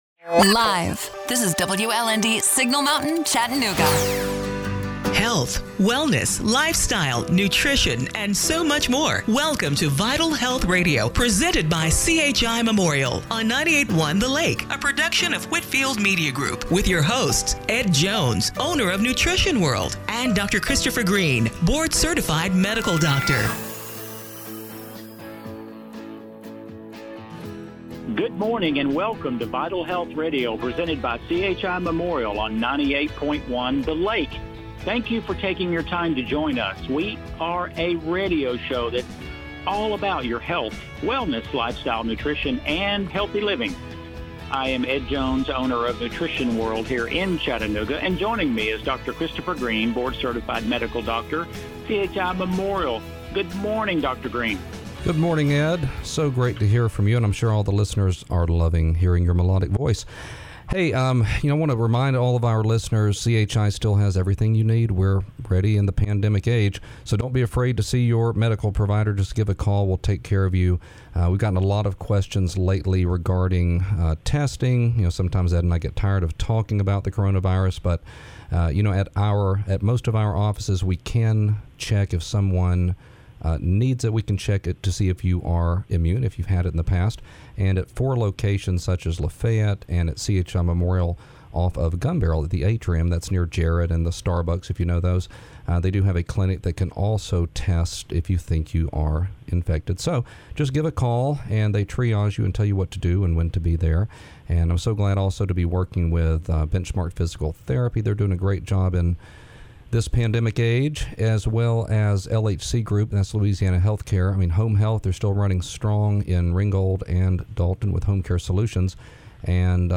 July 12, 2020 – Radio Show - Vital Health Radio